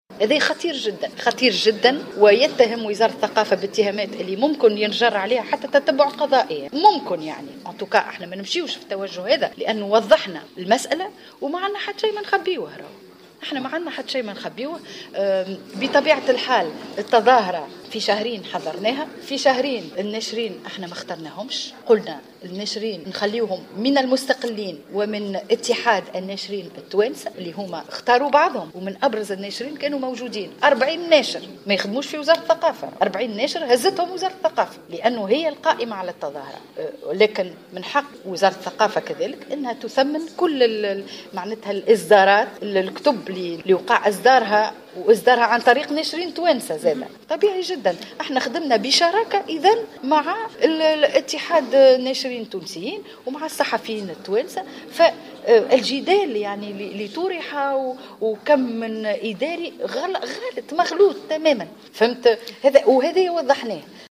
أكدت وزيرة الثقافة سنية مبارك في تصريح للجوهرة أف أم خلال افتتاحها اليوم الخميس 5 ماي 2016 المنتدى الخامس للمجتمع المدني والتراث بالمهدية أن الاتهامات الموجهة للوزارة بخصوص معرض جنيف الدولي للكتاب والصحافة "خطيرة جدا" ويمكن أن ينجر عنه تتبع قضائي وفق قولها.